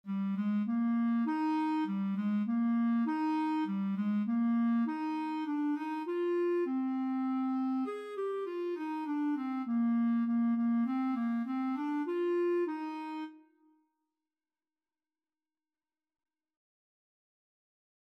Moderato
3/4 (View more 3/4 Music)
Eb major (Sounding Pitch) F major (Clarinet in Bb) (View more Eb major Music for Clarinet )
Instrument:
Clarinet  (View more Beginners Clarinet Music)
Traditional (View more Traditional Clarinet Music)